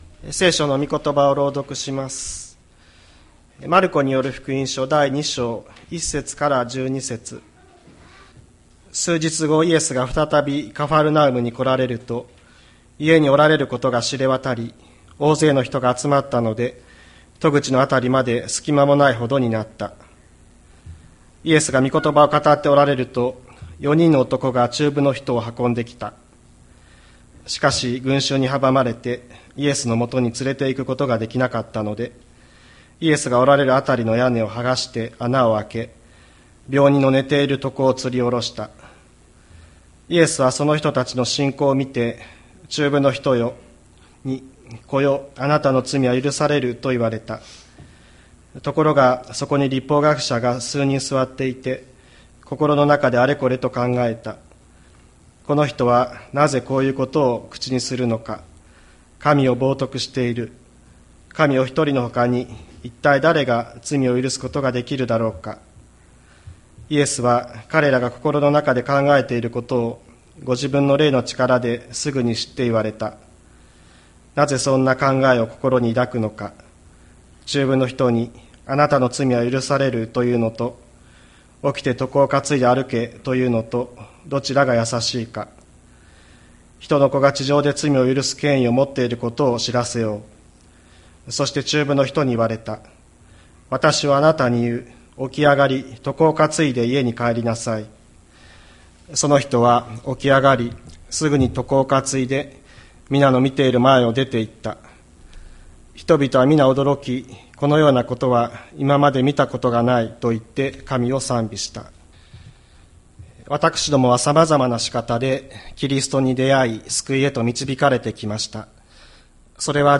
2024年08月04日朝の礼拝「あなたの罪は赦される」吹田市千里山のキリスト教会
千里山教会 2024年08月04日の礼拝メッセージ。